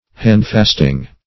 handfasting - definition of handfasting - synonyms, pronunciation, spelling from Free Dictionary
handfast \hand"fast`\, v. t. [imp.